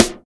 SNARE123.wav